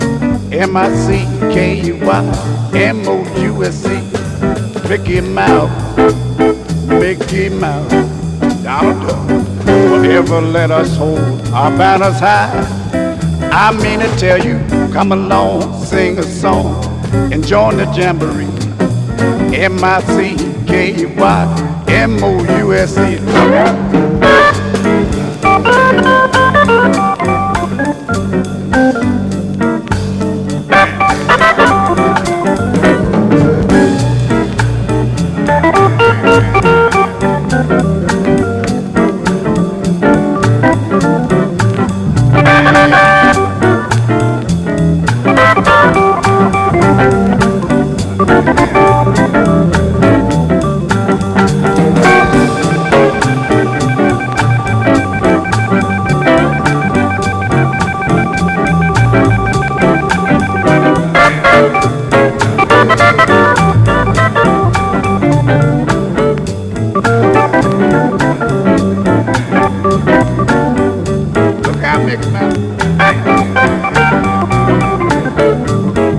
JAZZ / DANCEFLOOR / HARD BOP